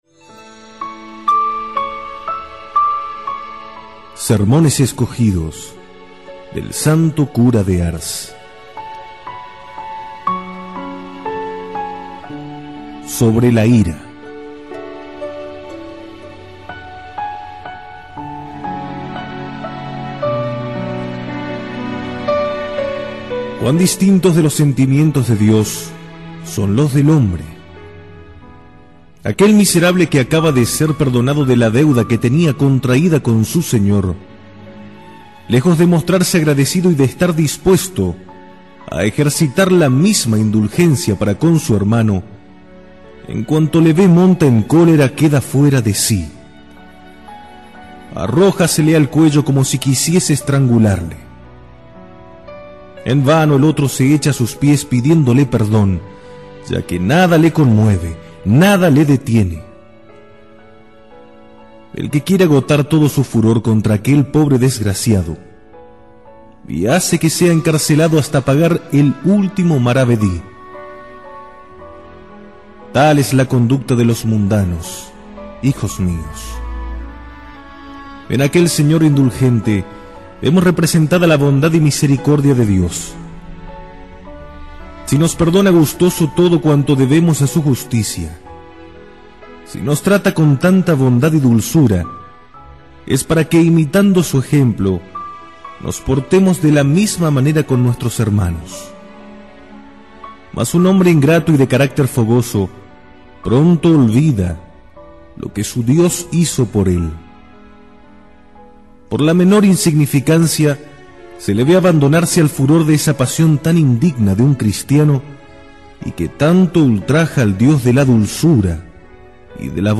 Audio–libro
Sermon-del-Santo-Cura-de-Ars-La-ira.mp3